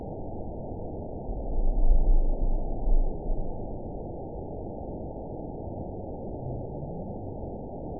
event 922665 date 03/01/25 time 16:26:28 GMT (3 months, 2 weeks ago) score 9.11 location TSS-AB10 detected by nrw target species NRW annotations +NRW Spectrogram: Frequency (kHz) vs. Time (s) audio not available .wav